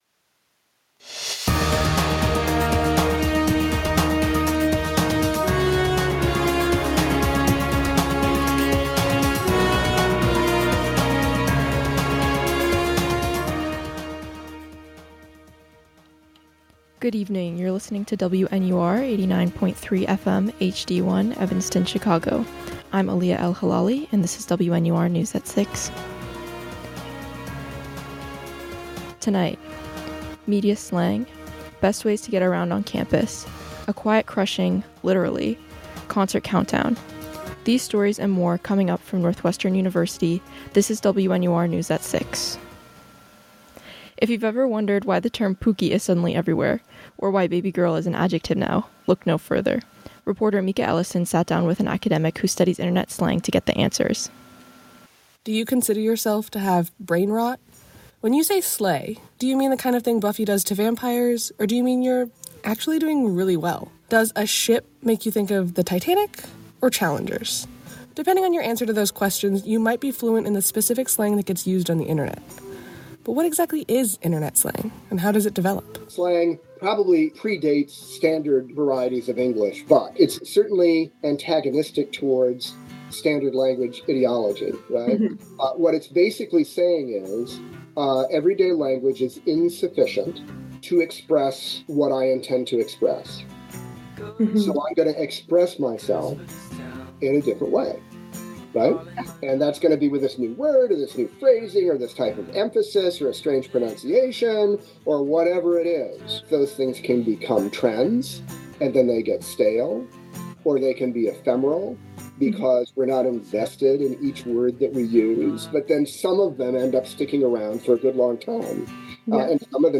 May 16, 2025: MEDIA SLANG, BEST WAYS TO GET AROUND ON CAMPUS, A QUIET CRUSHING…LITERALLY, CONCERT COUNTDOWN. WNUR News broadcasts live at 6 pm CST on Mondays, Wednesdays, and Fridays on WNUR 89.3 FM.